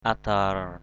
/a-d̪a:r/ (t.) nhè nhẹ = légèrement, doucement. lightly, softly. ndom adar _Q’ adR nói nhỏ = parler doucement à voix basse. speak softly. yam adar y’ adR bước nhè nhẹ...